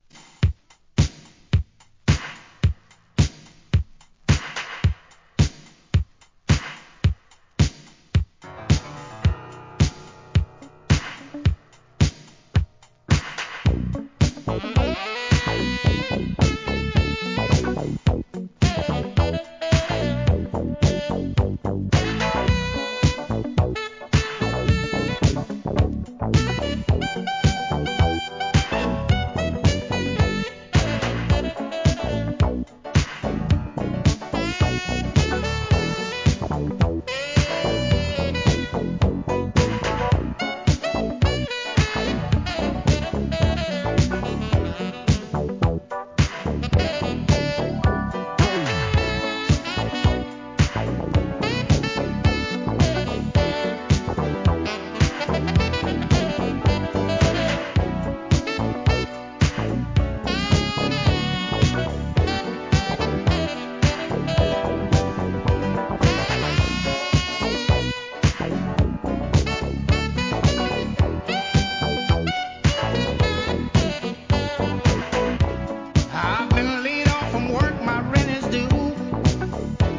SOUL/FUNK/etc...
イントロのSAXから素晴らしい1982年傑作FUNK!!